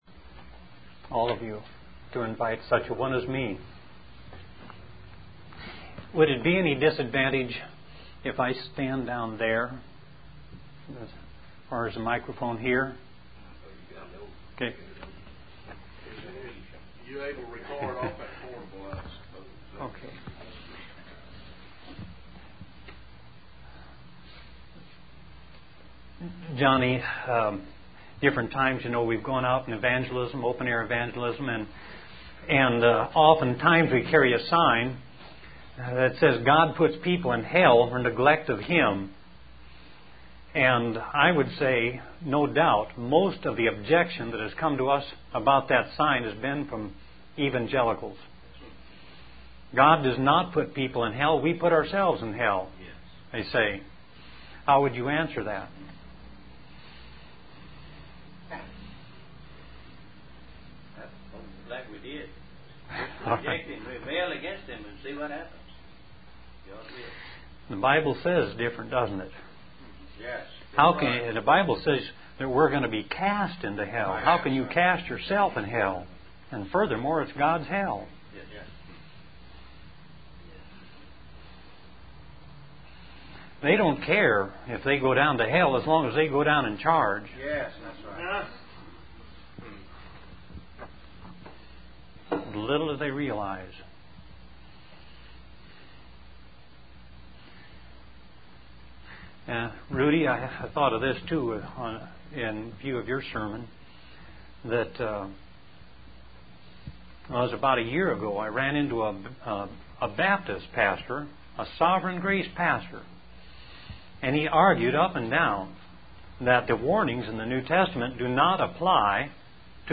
In this sermon, the preacher begins by acknowledging the small size of their congregation in the city of Beaumont and questioning the impact they can make. They pray for God's presence and guidance to equip them to serve in their current generation.